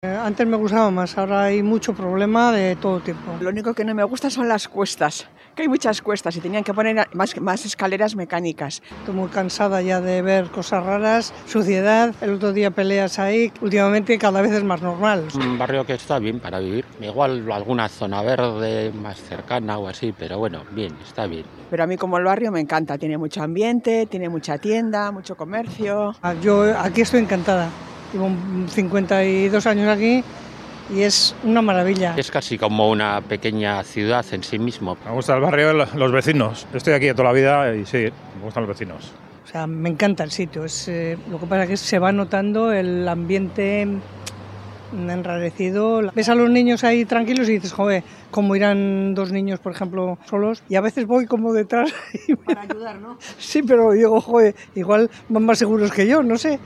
En 'Bilbao al habla' conocemos la opinión de los vecinos de Santutxu